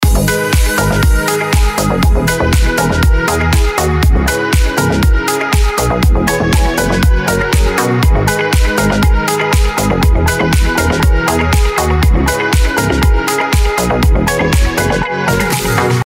• Качество: 320, Stereo
dance
без слов
club
Приятная танцевальная музыка